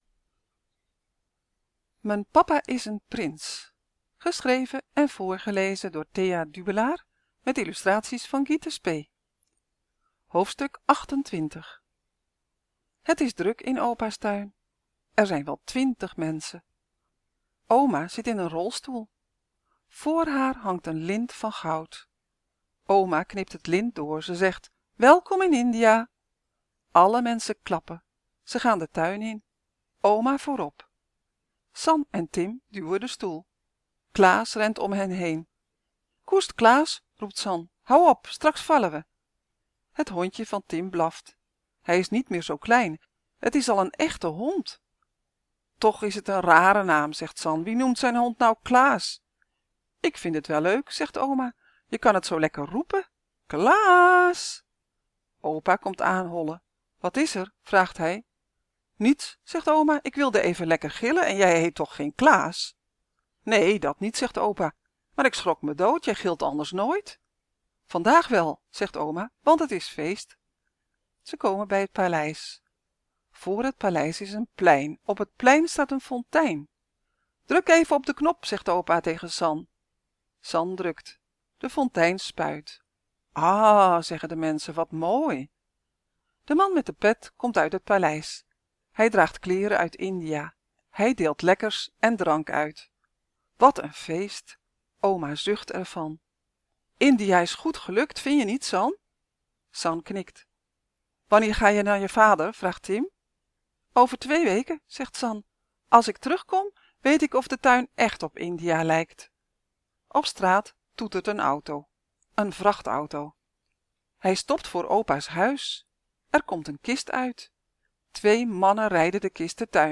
En haar papa is ook nog een echte prins. Nu ook als luisterboek (v.a. 5 jaar en ouder ) Vandaag hoofdstuk 28.